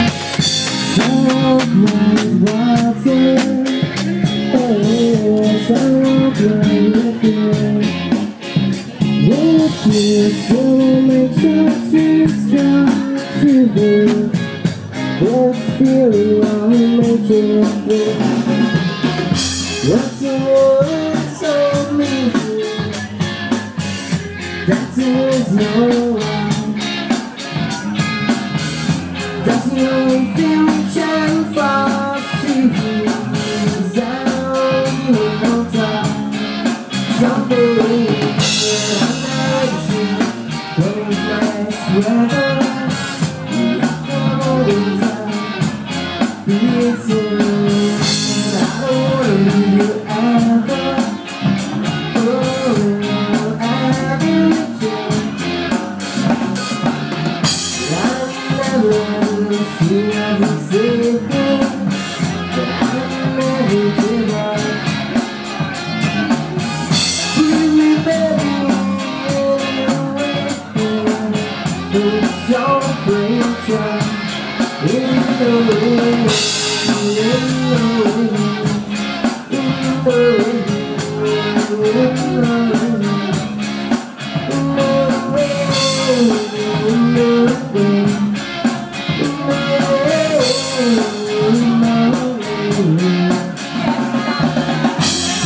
Live in Barga - first night - Piazza del Crocifisso